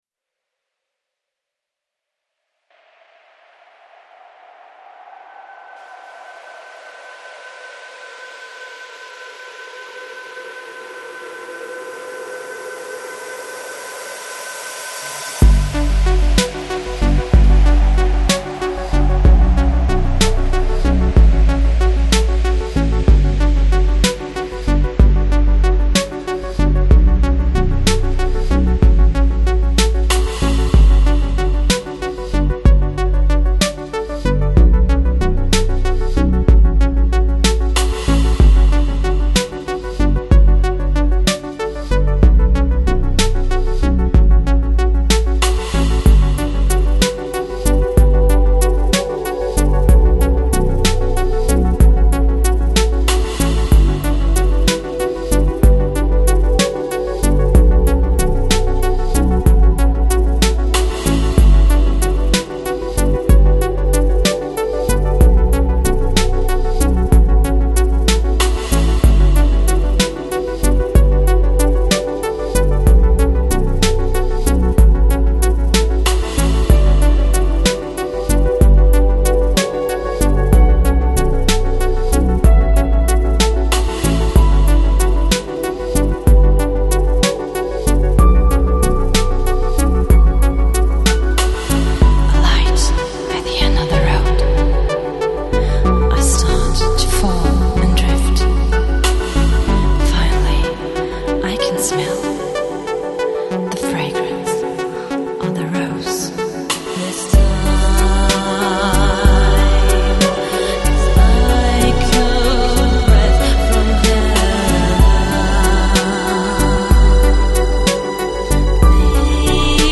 Lounge, Chill Out, Downtempo